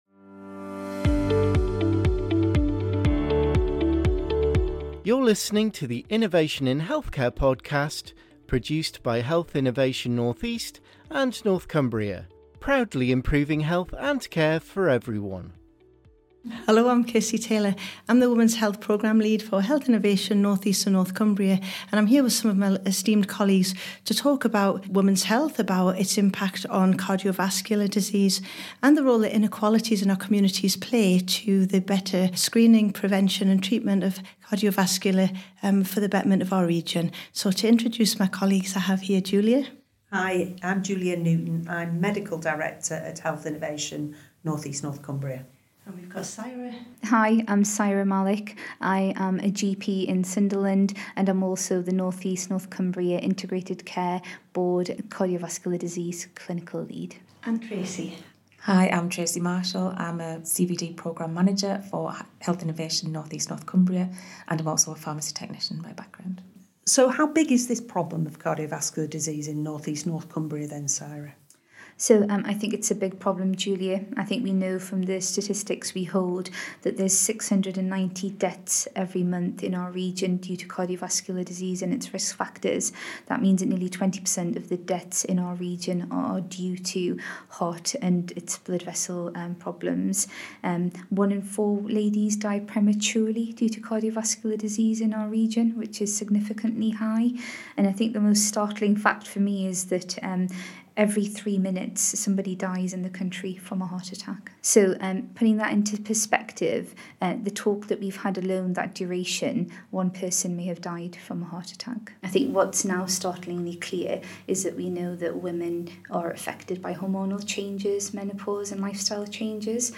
In this special podcast for International Women’s Day, our guests discuss how menopause can impact cardiovascular disease risk and why it’s important to have a healthy heart check and to know your numbers, especially when it comes to blood pressure and cholesterol levels.